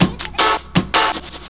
.wav 16 bit 44khz, Microsoft ADPCM compressed, mono,
Lmstep2.wav Breakbeat 34k